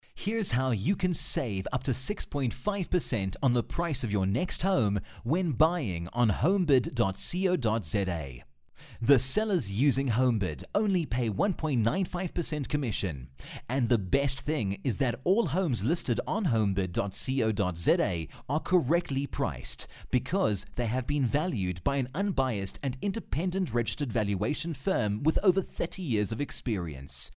Masculino
Inglês - Sul Africano